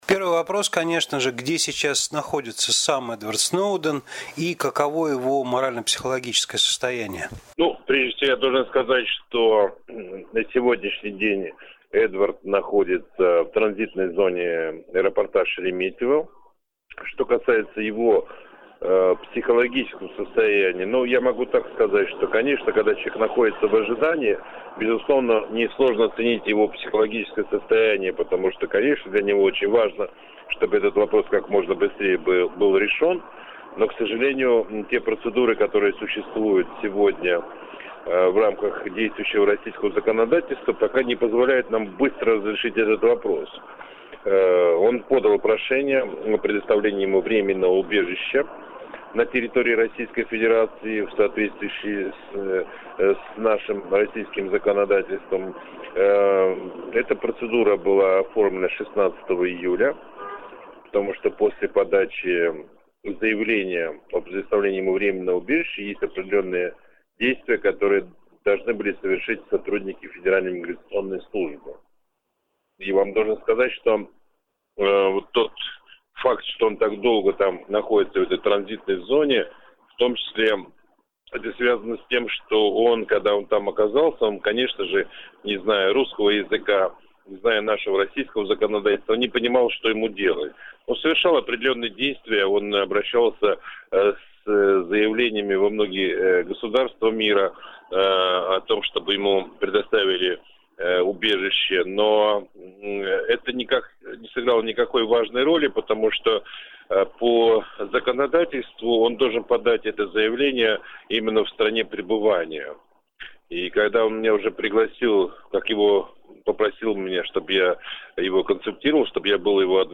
Российский адвокат дал интервью Русской службе «Голоса Америки»